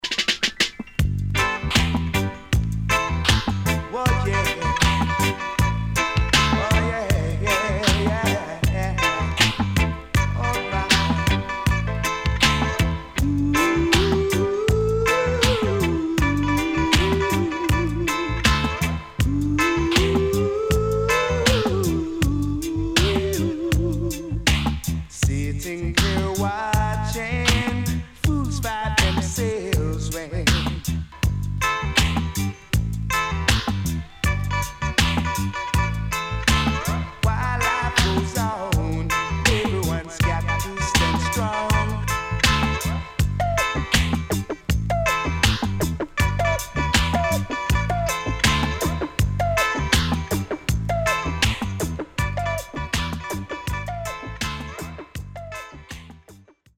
HOME > Back Order [DANCEHALL DISCO45]  >  定番DANCEHALL
SIDE A:少しチリノイズ入りますが良好です。